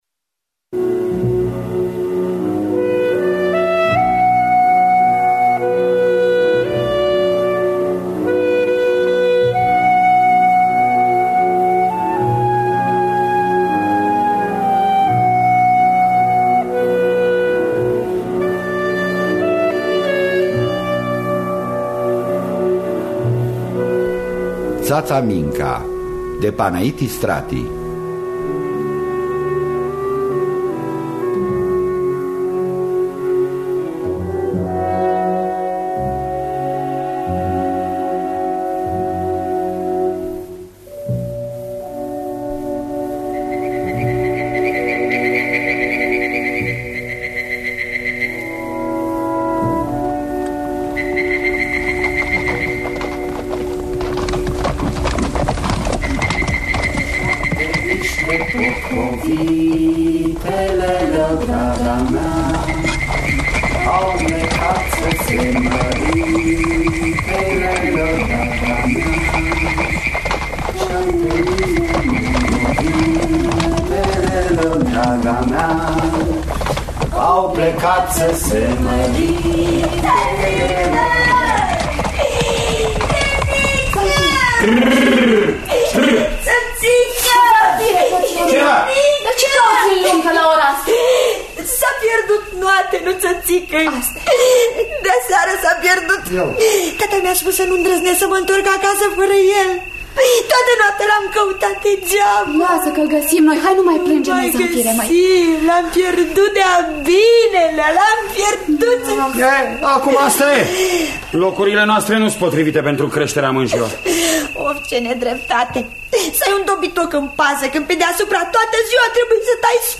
Ţaţa Minca de Panait Istrati – Teatru Radiofonic Online